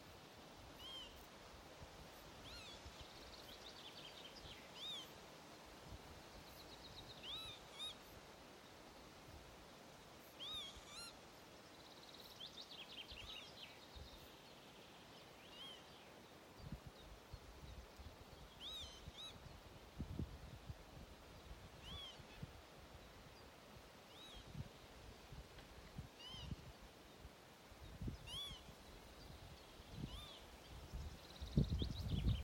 Birds -> Waders ->
Northern Lapwing, Vanellus vanellus
Administratīvā teritorijaValkas novads